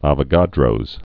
A·vo·ga·dro's law
və-gädrōz, ävō-)